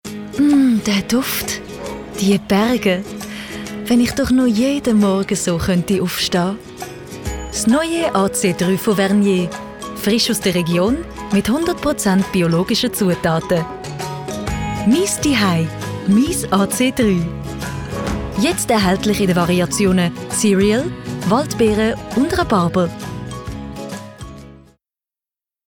Werbung Schweizerdeutsch (ZH)
Schauspielerin mit breitem Einsatzspektrum